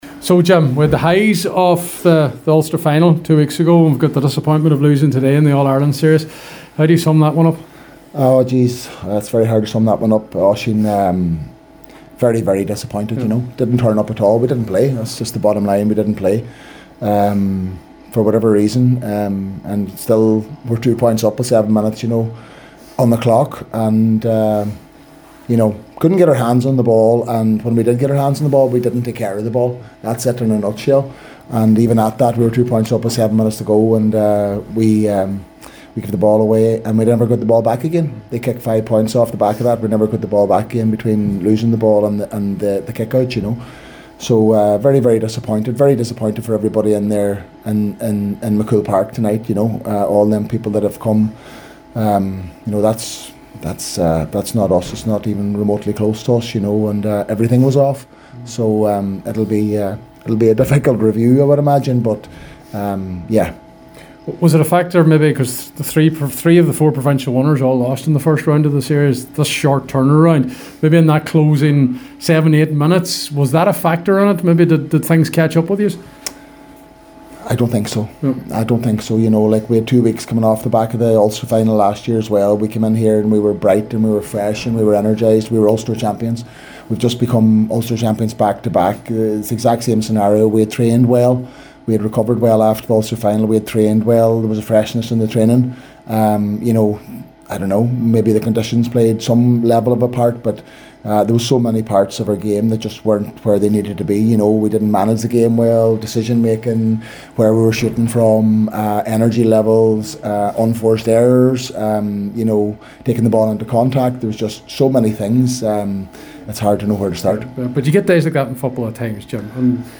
Donegal manager Jim McGuinness
McGuinness said “that’s not us, it’s not even remotely close to us”…